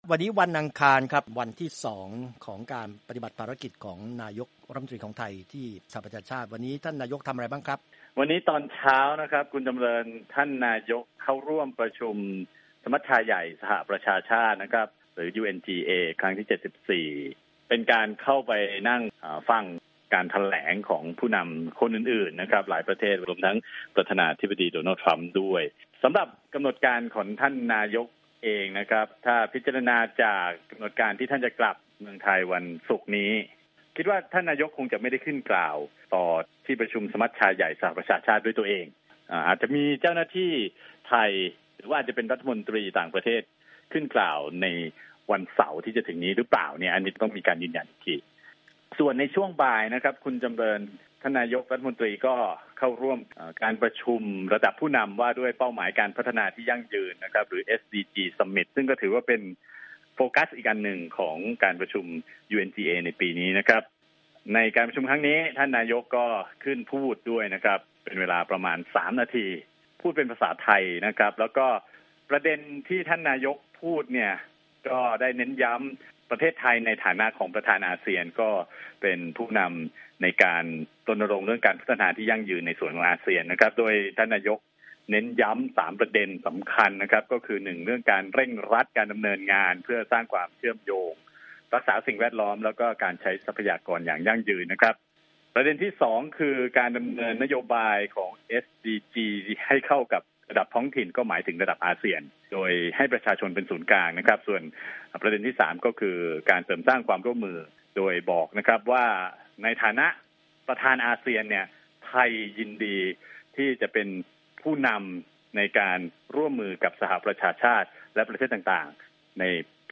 Thai Prime Minister gives speech at UN's SDG Summit 2019